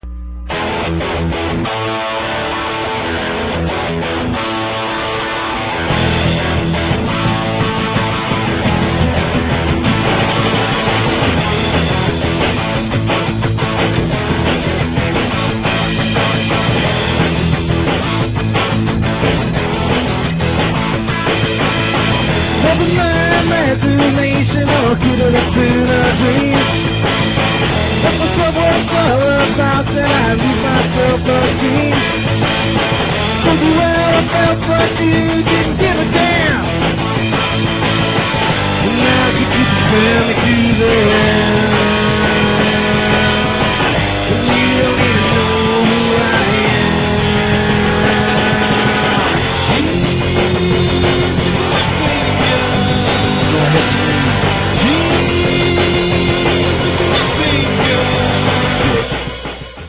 OLD 4-TRACK STUFF